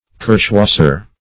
Search Result for " kirschwasser" : The Collaborative International Dictionary of English v.0.48: Kirschwasser \Kirsch"was`ser\, n. [G., fr. kirsche cherry + wasser water.] An alcoholic liquor, obtained by distilling the fermented juice of the small black cherry.